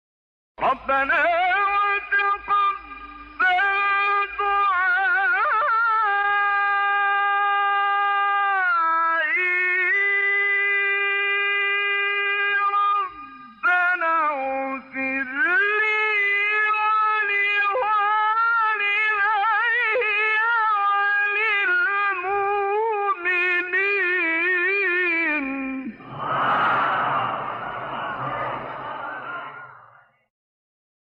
گروه شبکه اجتماعی: مقاطعی از قاریان مصری که در مقام رست اجرا شده است، می‌شنوید.
مقام رست